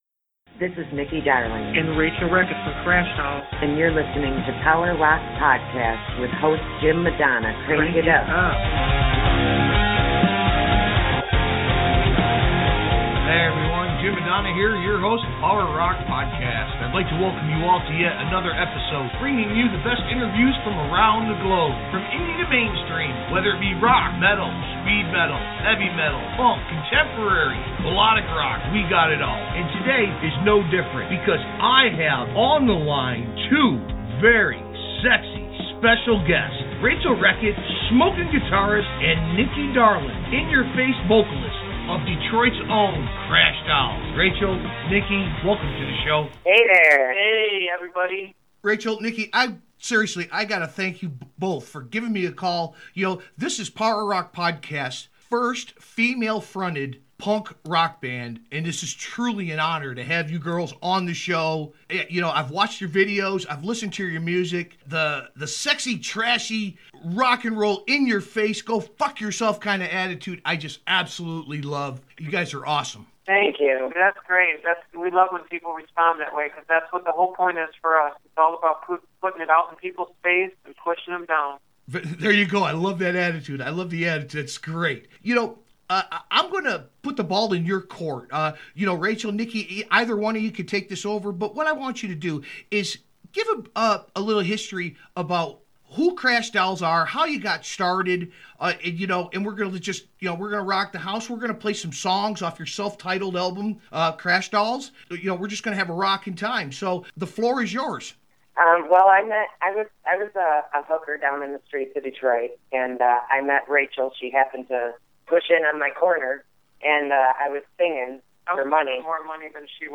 CrashDollz-Interview.mp3